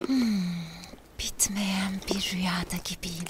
Play, download and share 1. ımm original sound button!!!!
ulker-cikolata-fndk-ruyas-asmr-mp3cut.mp3